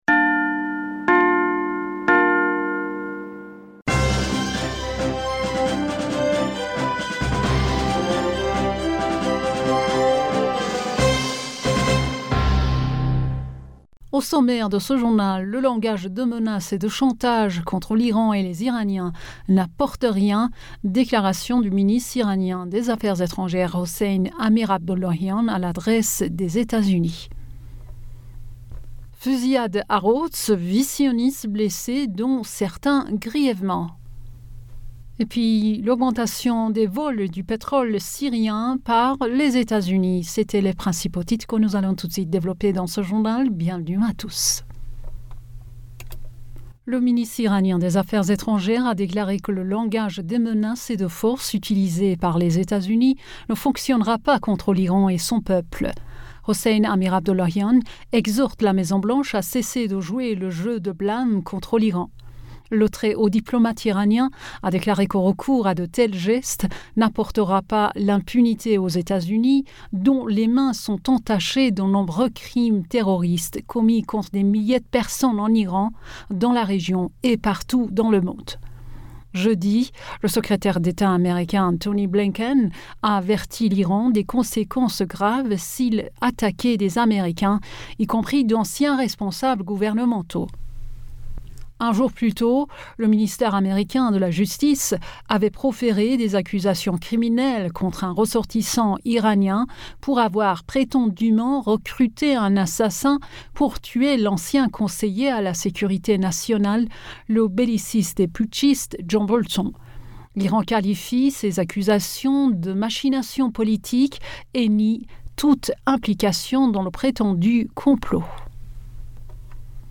Bulletin d'information Du 14 Aoùt